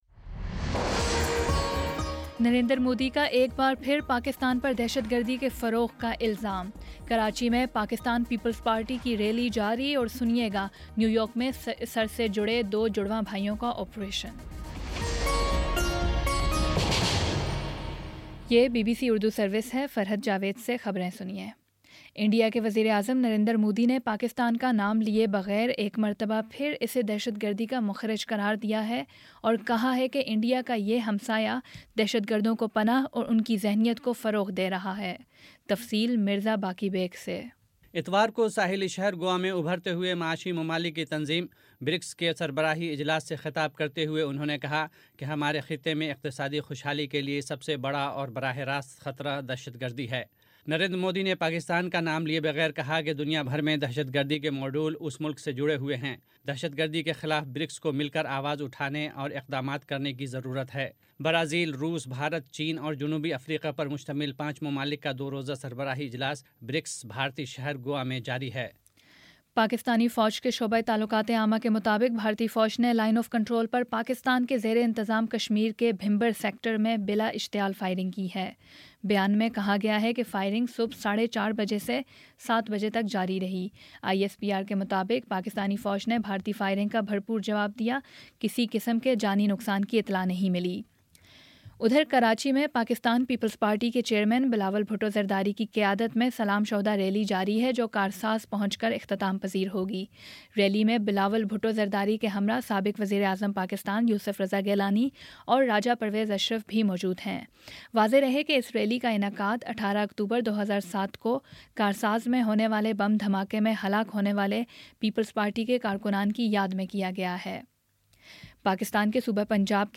اکتوبر16 : شام سات بجے کا نیوز بُلیٹن